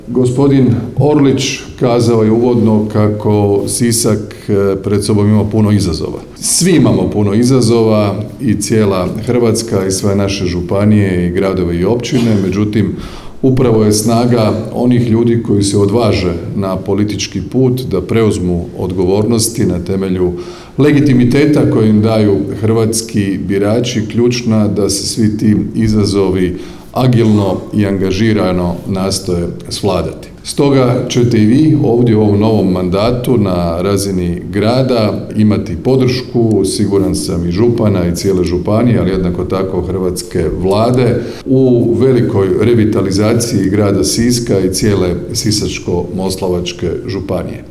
Tim povodom u srijedu, 4. lipnja 2025. godine, održana je svečana sjednica Gradskog vijeća Grada Siska.
Svečanoj sjednici Gradskog vijeća prisustvovao je i predsjednik Vlade RH Andrej Plenković